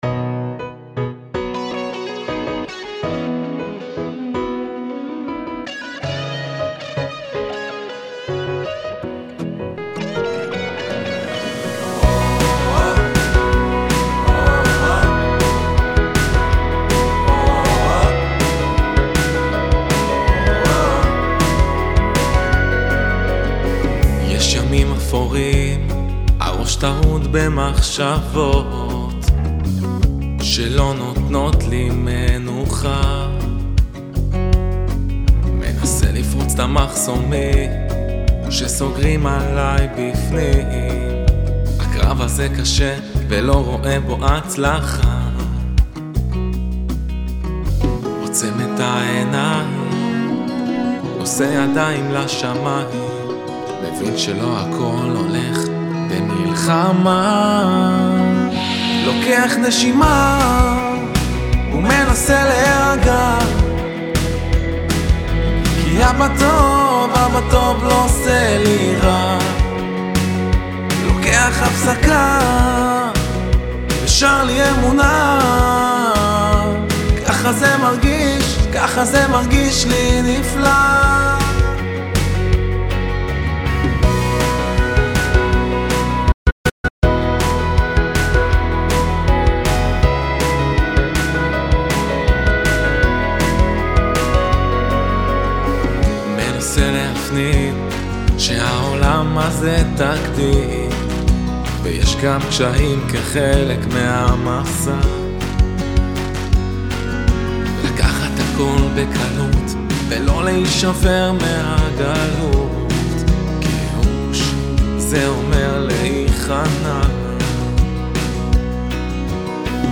אני שמח לשתף אתכם סקיצה לשיר נוסף שלי - שר לי אמונה.
שר לי אמונה mp3 מושלם חוץ מזה שצריך לחדד את המילים שיתאימו יותר ללחן המטורף וגם צריך מיקס חובה (נראה לי שזה בלי מיקס תגיד אם אני צודק) ואתה יכול לשחרר את זה כי זה להיט! עריכה: מה זה העצירה הזאת בדרופ? (מעבר) ב1:26 אני חושב שזה מיותר